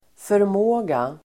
Uttal: [förm'å:ga]